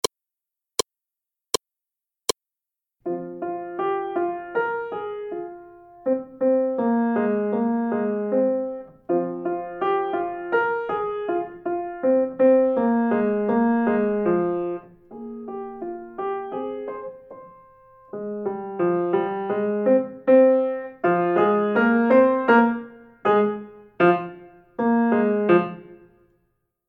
연습